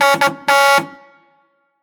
Klaxon
Le buzzer